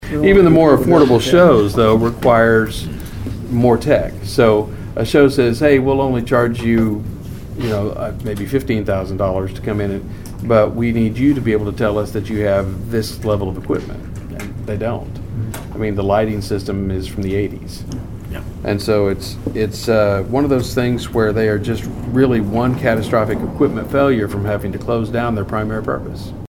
Ward 1 Councilor Tim Sherrick says The Center is losing revenue opportunities because of the antiquated system, which is